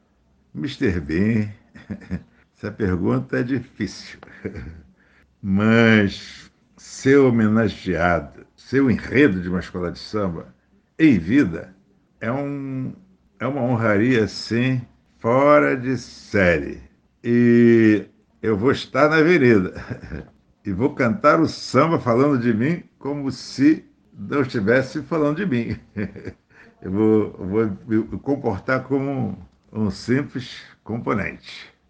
Em entrevista exclusiva ao programa, Martinho falou do carnaval 2022, da homenagem que receberá da Vila Isabel, do samba que gostaria de ter feito, de seus parceiros e do novo álbum que vai lançar em março, “Mistura Homogênea”.